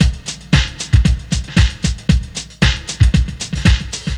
• 115 Bpm 90's Drum Loop Sample B Key.wav
Free breakbeat sample - kick tuned to the B note. Loudest frequency: 865Hz